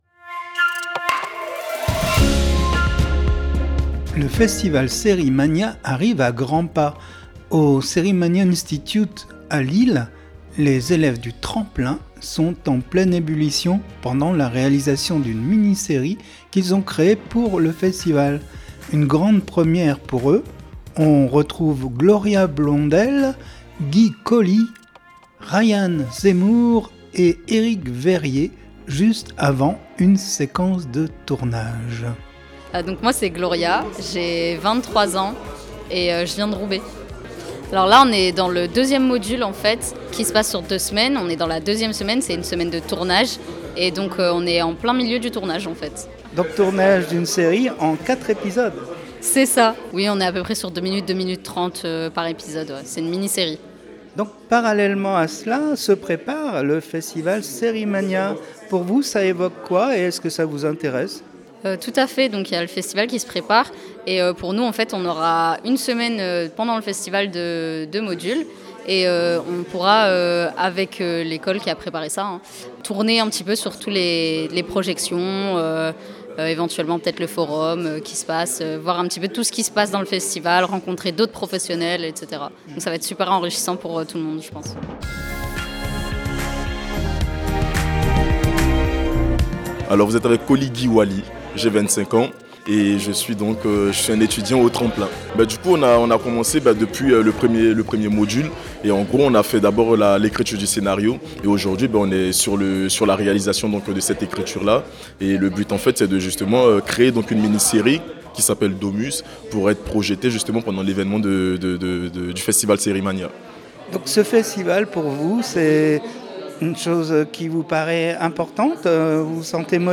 Rencontre avec les élèves du Tremplin de Series Mania Institute – SMI avant une séquence de tournage, ils nous en disent plus sur les métiers de l’audiovisuel :